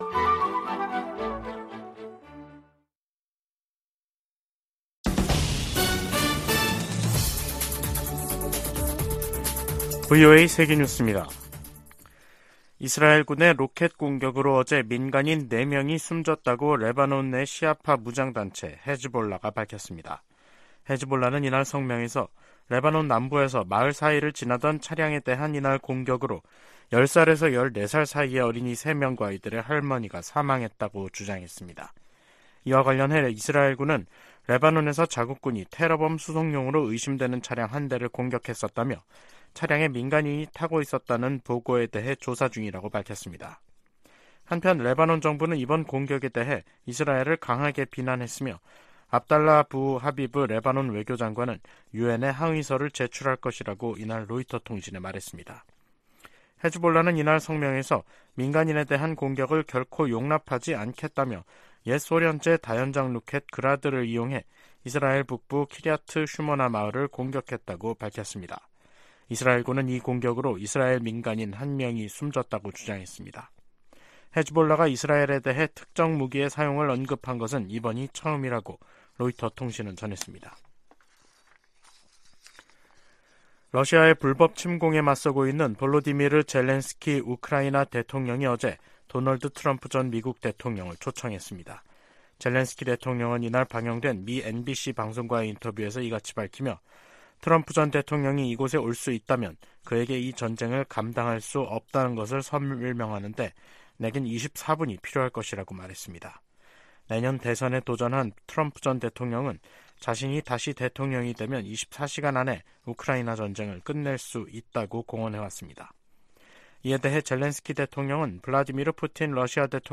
VOA 한국어 간판 뉴스 프로그램 '뉴스 투데이', 2023년 11월 6일 2부 방송입니다. 미 국방부는 북한이 미 대륙간탄도미사일 '미니트맨3' 시험발사를 비난한 데 대해 북한의 군사적 위협을 지적했습니다. 미 상원의원들이 북한과 러시아 간 군사 협력 확대가 전 세계를 위협한다며 단호한 국제적 대응을 촉구했습니다. 유엔 식량농업기구(FAO)는 17년재 북한을 외부의 식량 지원 필요국으로 지정했습니다.